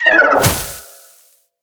File:Sfx creature penguin death land 01.ogg - Subnautica Wiki
Sfx_creature_penguin_death_land_01.ogg